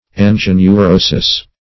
angioneurosis - definition of angioneurosis - synonyms, pronunciation, spelling from Free Dictionary
angioneurosis \an`gi*o*neu*ro"sis\
([a^]n`j[i^]*[-o]*n[-u]*r[=o]"s[i^]s), n. [NL.; angio- +